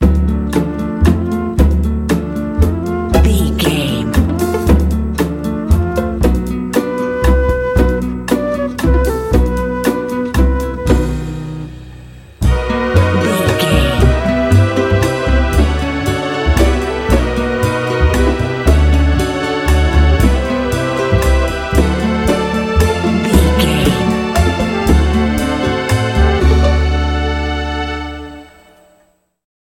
Aeolian/Minor
mellow
foreboding
strings
flute
acoustic guitar
whimsical
cinematic